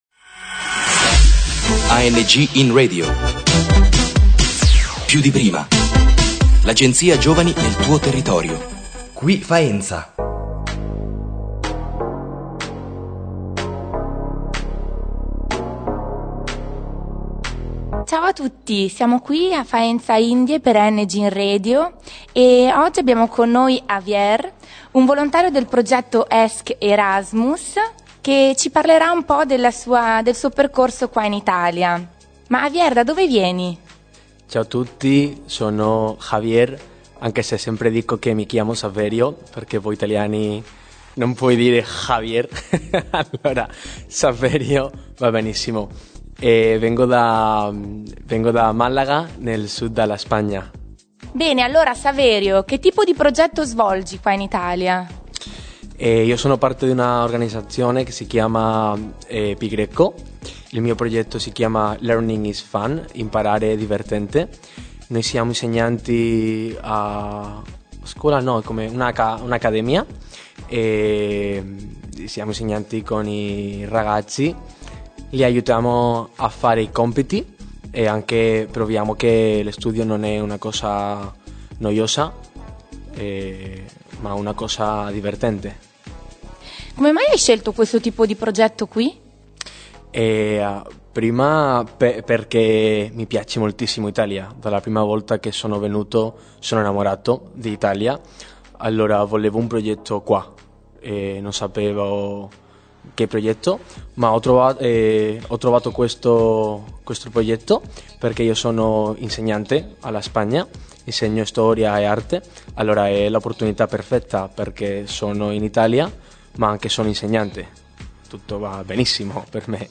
AngInRadio Faenza indie: intervista